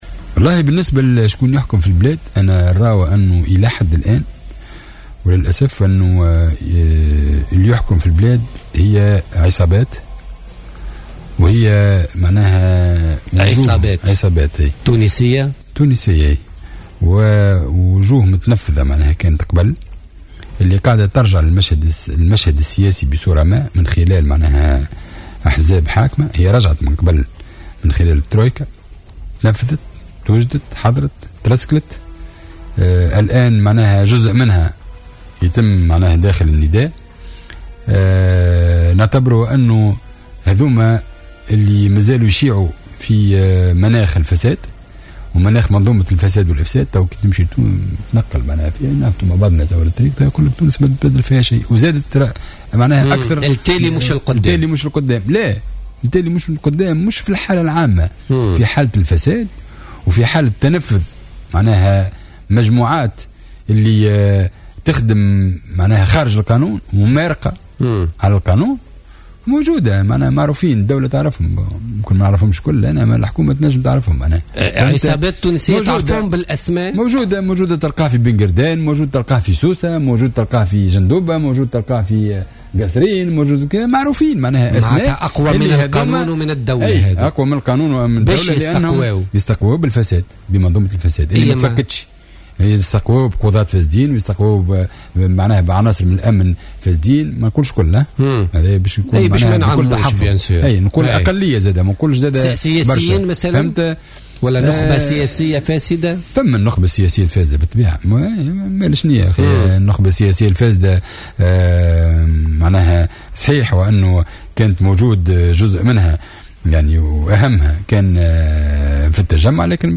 وقال الرحوي في حوار مع الجوهرة أف أم اليوم الخميس، أن هؤلاء أقوى من القانون ومن الدولة، واستمدوا نفوذهم بفضل فئة صغيرة من القضاة والأمنيين الفاسدين، مشيرا إلى أن الوضع سيبقى على ما هو عليه طالما لم تقدر أي حكومة على طرح هذه القضية ومحاولة محاربة الفساد وتفكيك هذه المنظومة انطلاقا من حكومة الجبالي ووصولا إلى حكومة الحبيب الصيد.